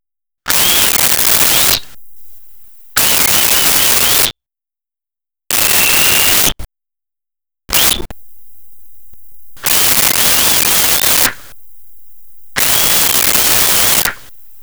Whistles Single
Whistles Single.wav